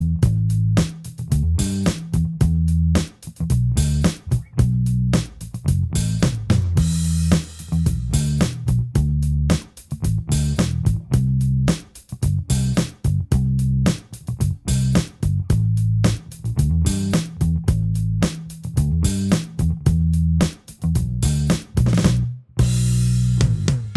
Minus Guitar Solos Soft Rock 4:53 Buy £1.50